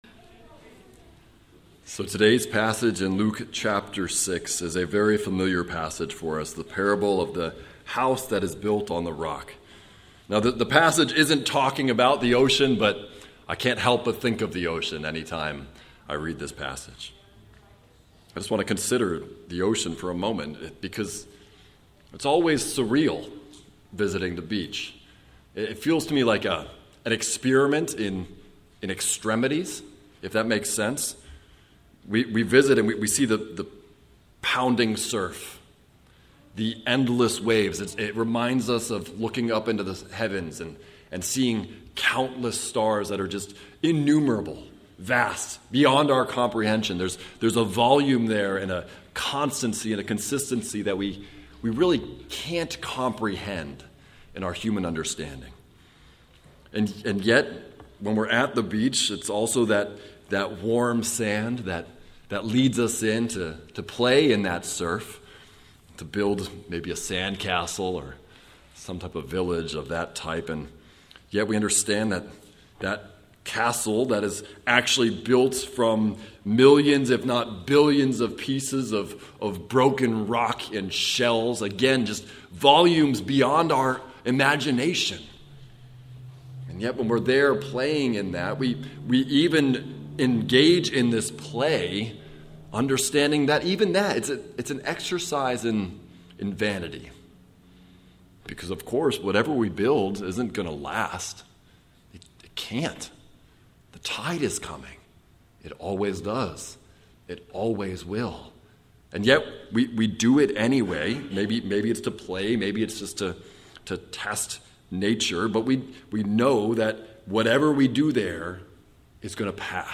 Luke: Sermon on Discipleship – A Foundation of Rock - Waynesboro Bible Church